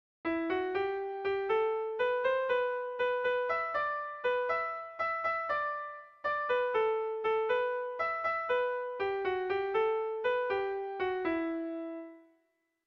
Erlijiozkoa
8A / 8A / 8B / 8B